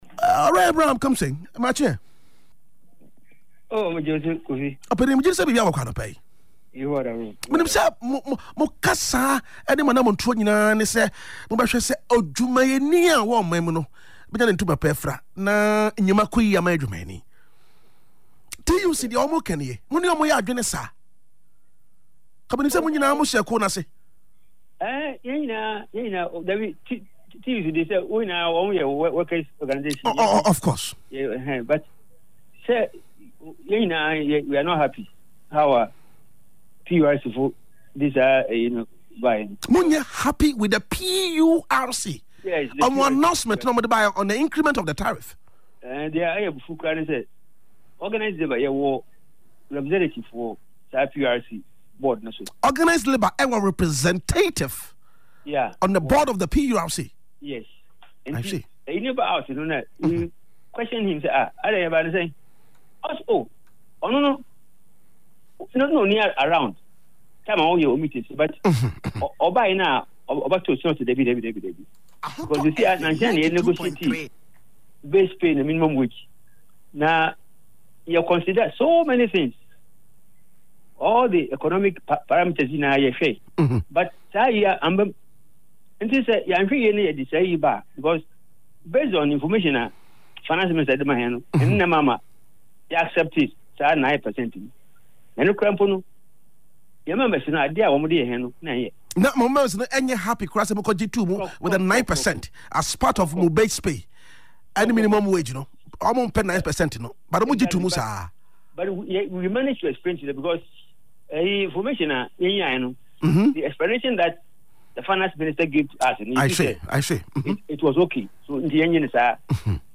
Speaking on Ahotor FM’s Adekyee Mu Nsem on Tuesday